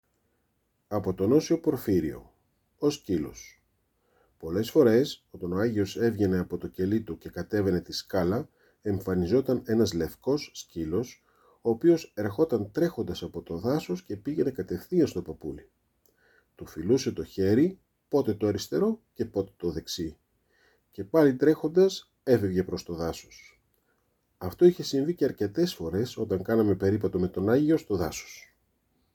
Erzählung aus der Vita des Heiligen Porphyrios Kausokalybites. „Der Hund“: Es geht um einen weißen Hund, der jedes Mal, wenn der heilige Porphyrios aus seiner Zelle kam, zu ihm lief.